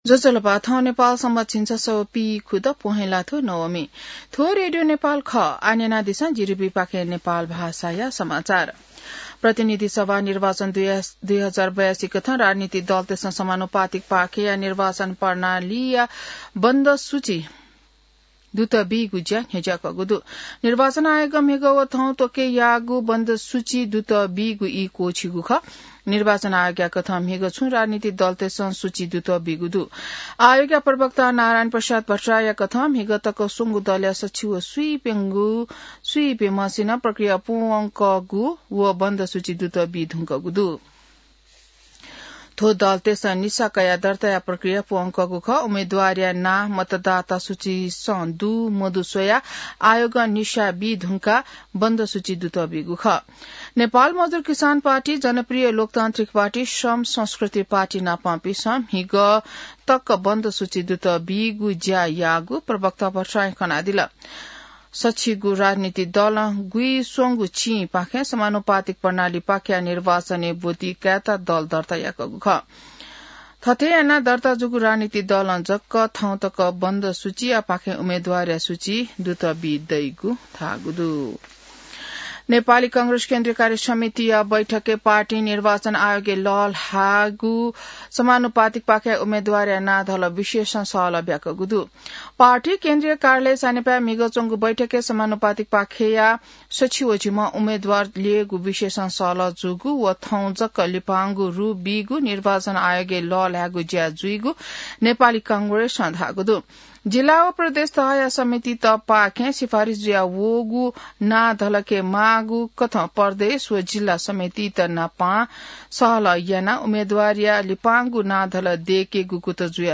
नेपाल भाषामा समाचार : १४ पुष , २०८२